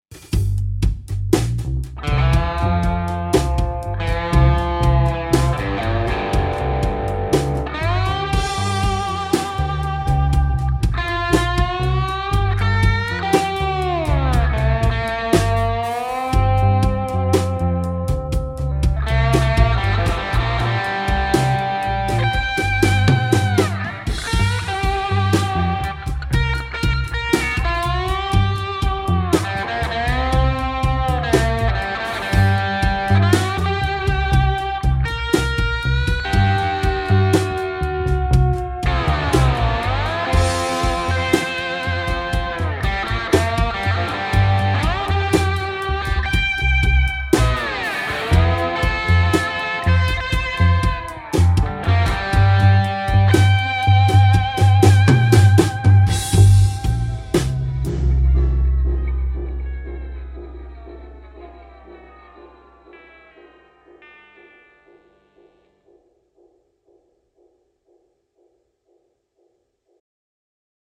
Hyvä slidesoundi, muutenkin virkistävä lähestymistapa.
uskottava slide soundi, hyviä lickejä.
Maukasta putkittelua!